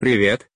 Мужской голос робота привет
• Категория: Привет(приветствие)
• Качество: Высокое
На этой странице вы можете прослушать звук мужской голос робота привет.